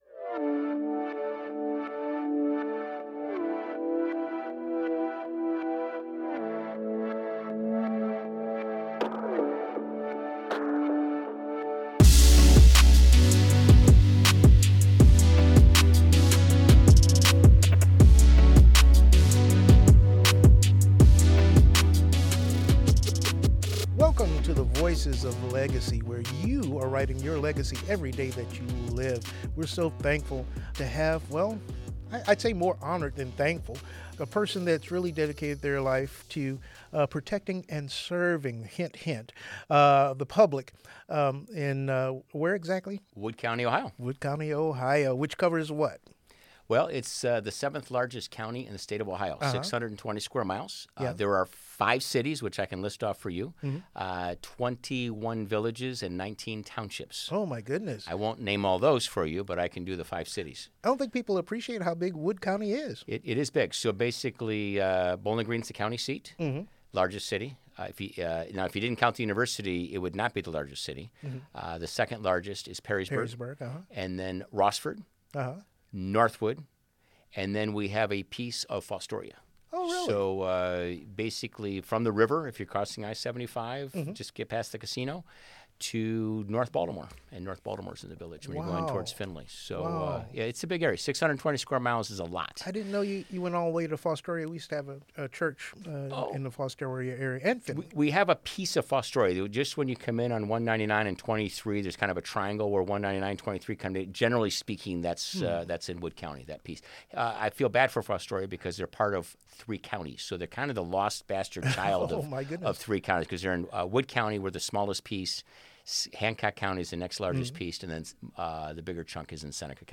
Service, Safety, and Legacy: A Conversation with Sheriff Mark Wasylyshyn - WGTE Public Media